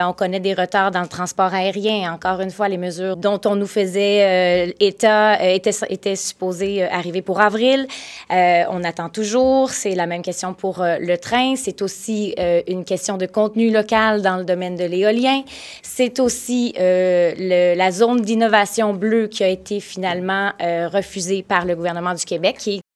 La porte-parole nationale du PQ, Méganne Perry-Mélançon, a tenu un point de presse ce matin à l’Assemblée nationale, en compagnie de Joël Arseneau.